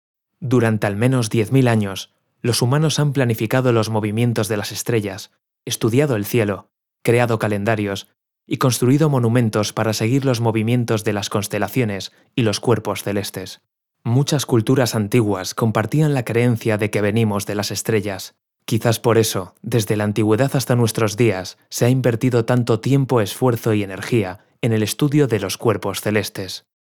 Kommerziell, Natürlich, Urban, Cool, Warm
Audioguide